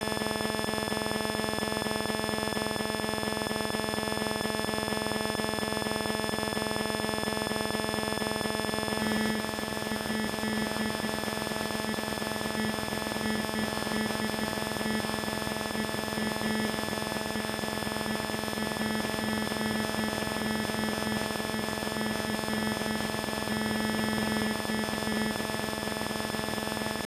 BCCH control channel of a GSM network that utilizes a single non-hopping ARFCN.
GSM_BCCH.wav